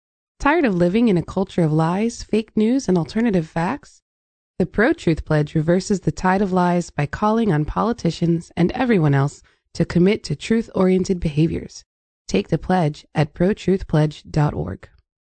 PSAs for Podcasts and Radio Shows